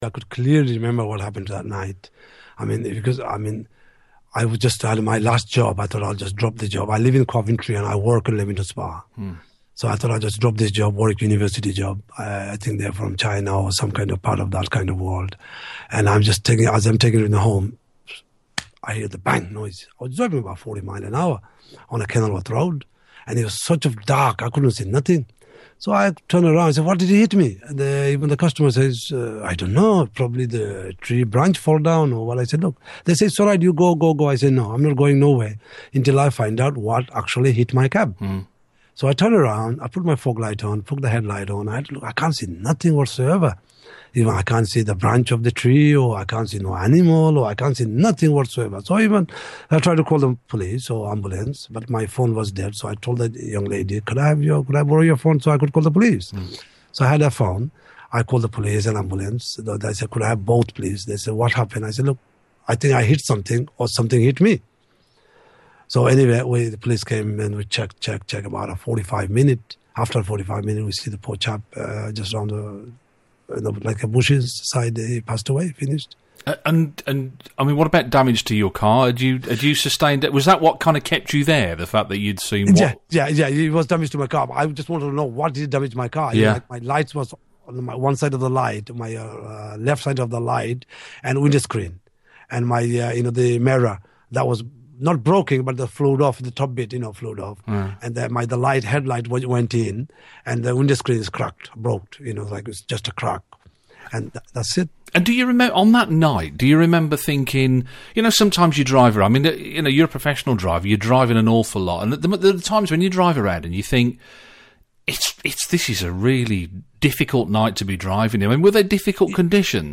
Street Lights interview